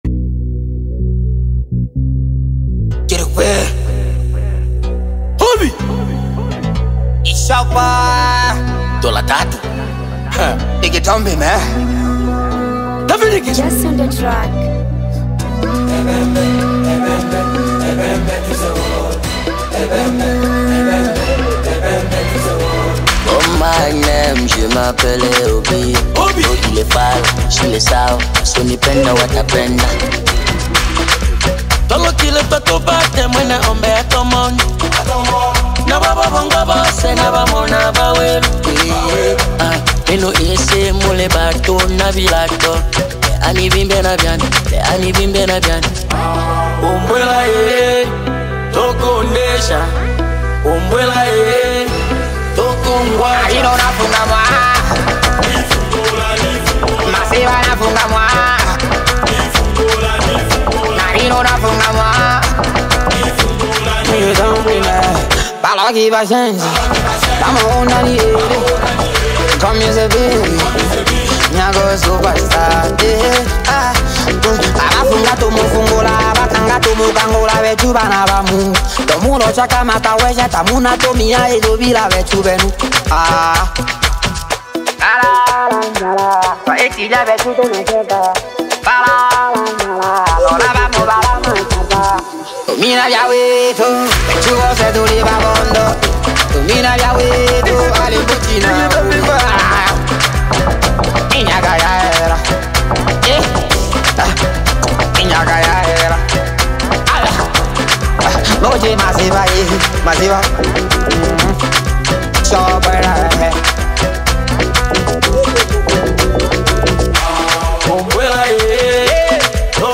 East African music